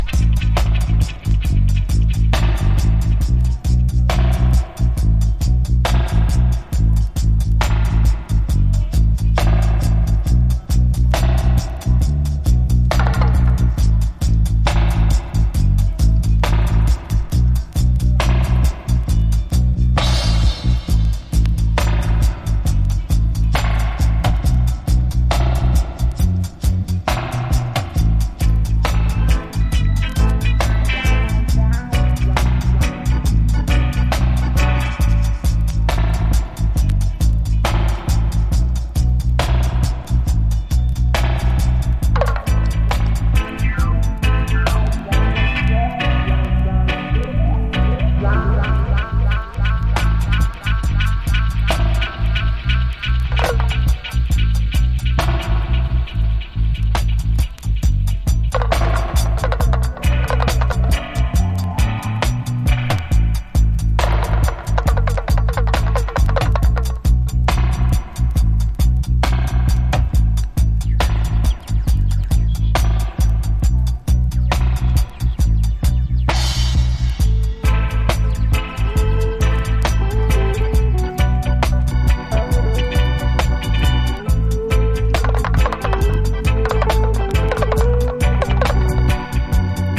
台風が過ぎるようなエフェクトのイントロからはじまる